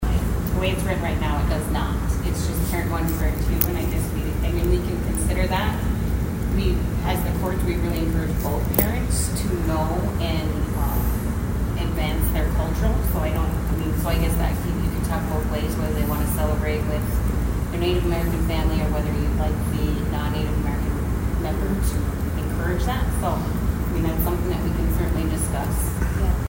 Judge Klinger offered an answer to that question.